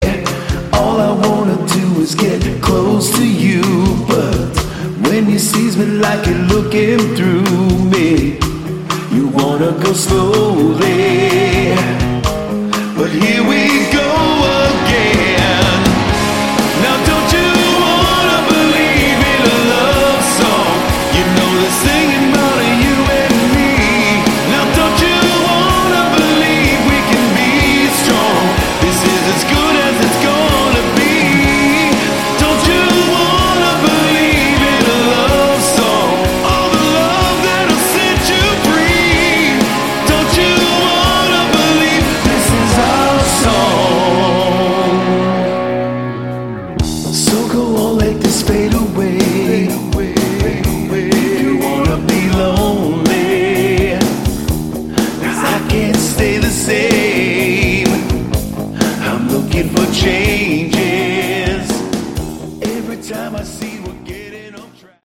Category: Melodic Rock
Vocals, Guitars, Keyboards
Rhythm Guitars, Acoustic Guitars, Keyboards, Backing Vocals
Drums, Backing Vocals
Bass, Backing Vocals
Lead Guitars, Backing Vocals